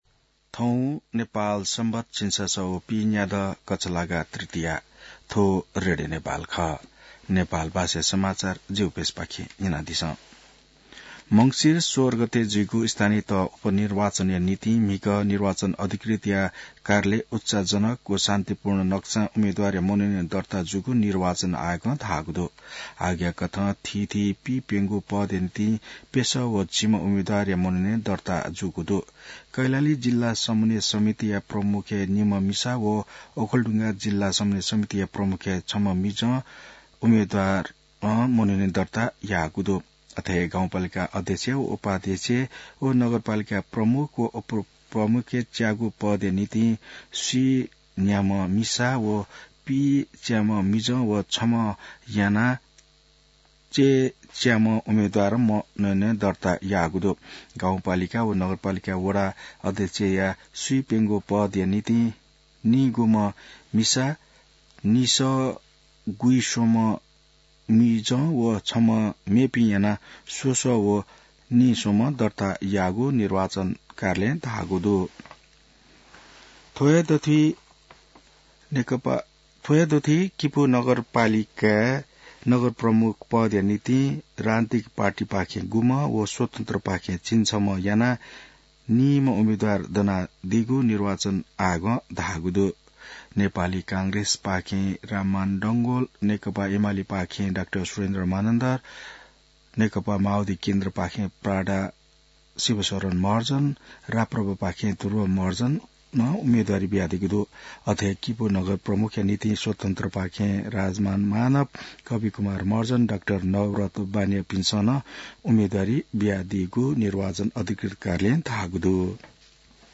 नेपाल भाषामा समाचार : ४ मंसिर , २०८१